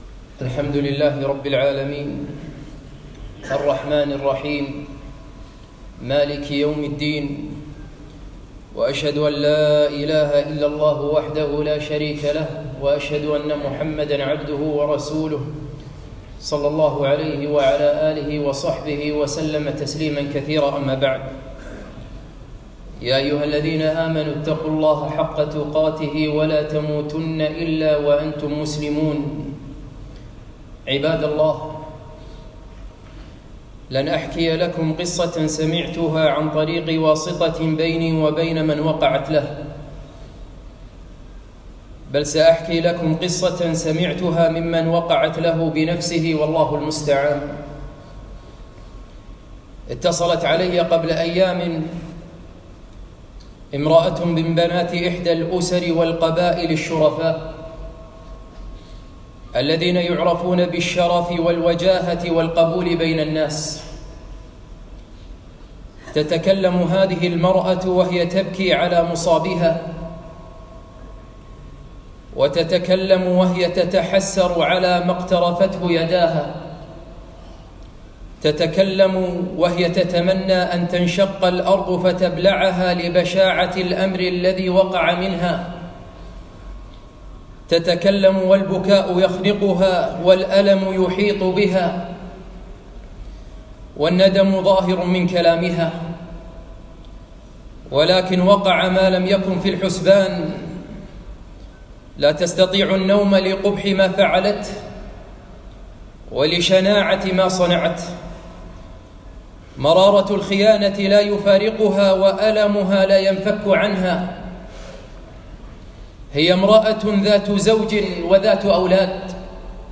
خطبة - إنه كان فاحشة وساء سبيلا - دروس الكويت